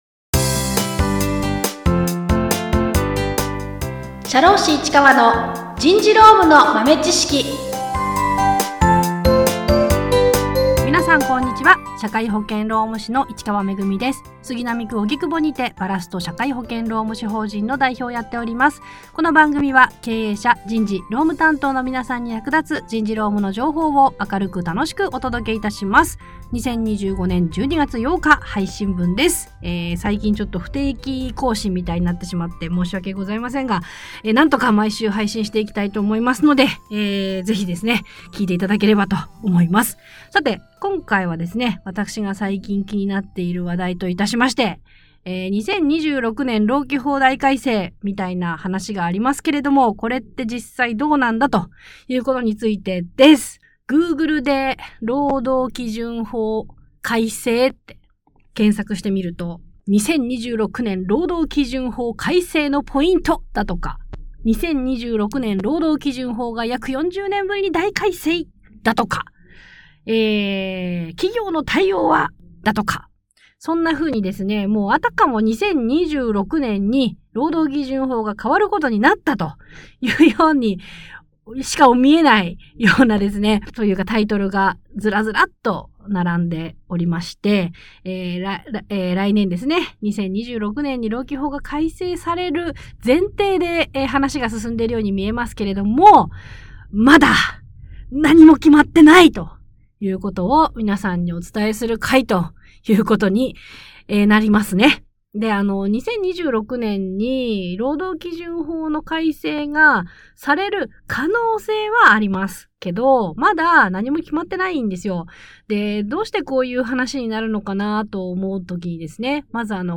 ※たまにバリバリという雑音が入ってしまっています。